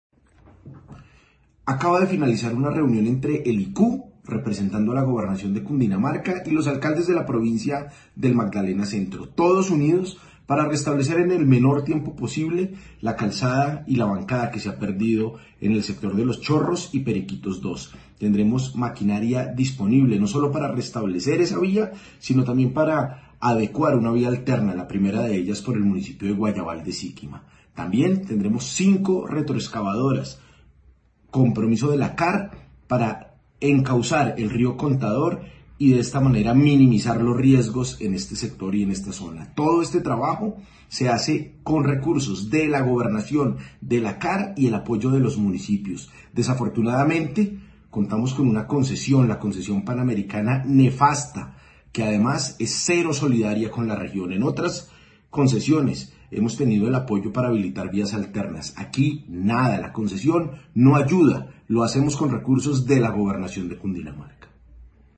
Declaración de Nicolás García Bustos, gobernador de Cundinamarca.